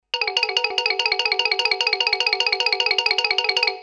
Cortina musical cómica
cómico
Sonidos: Música